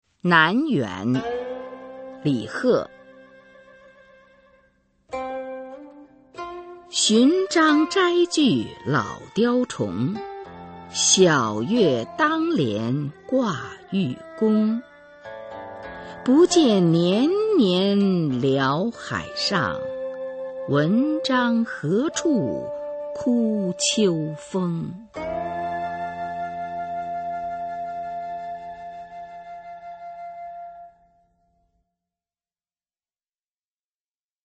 [隋唐诗词诵读]李贺-南园 配乐诗朗诵